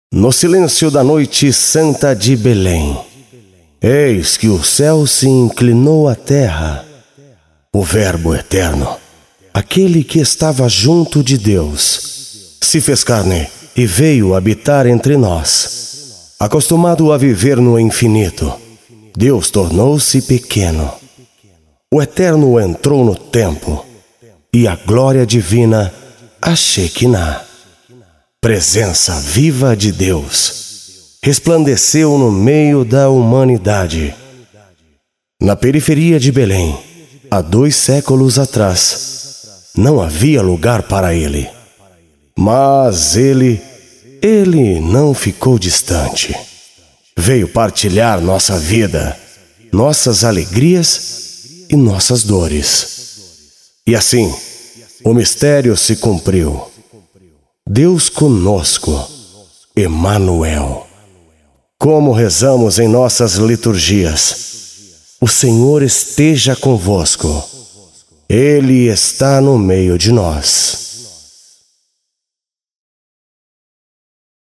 abertura especial de Natal OFF: